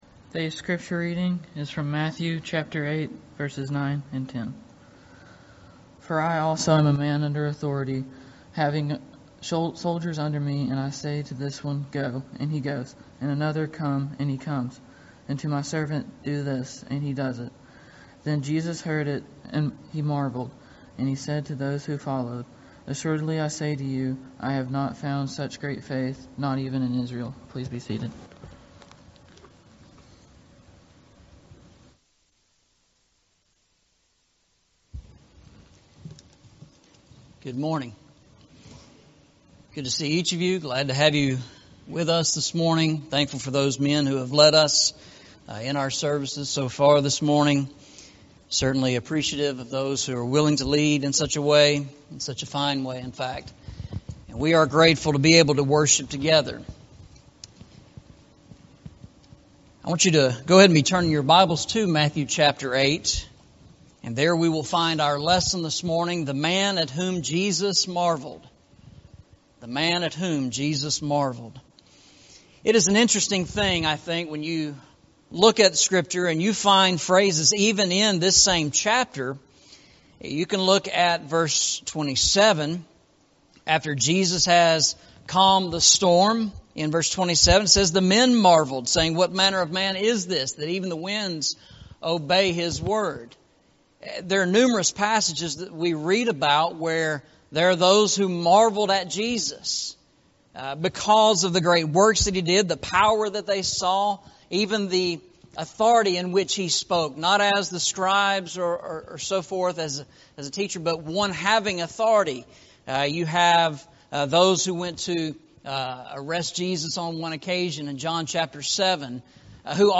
Eastside Sermons Service Type: Sunday Morning « New Testament Truths Presented by Old Testament Shadows Adam